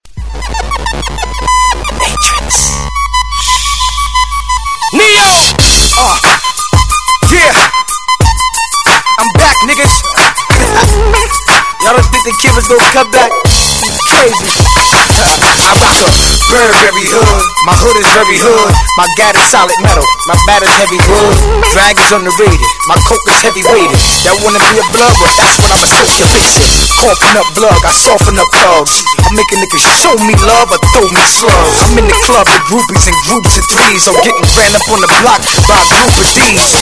Rap music